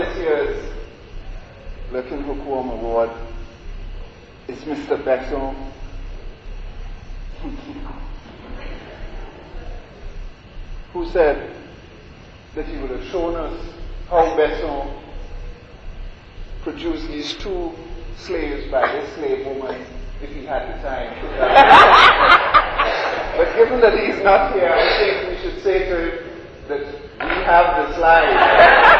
OP59 - Lurking Hookworm Award: Speech. Association of Caribbean Historians Annual Conference 1990, Trinidad and Tobago
l audio cassette